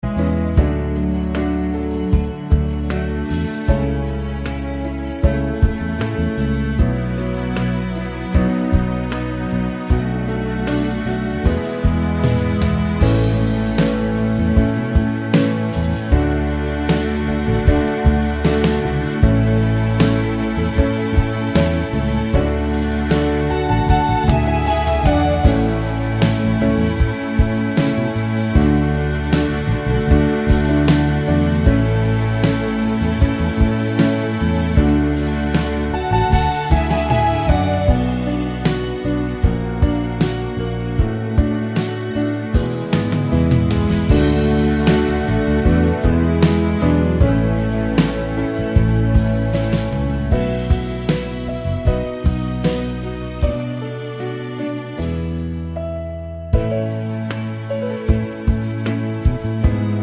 no Backing Vocals Soundtracks 3:13 Buy £1.50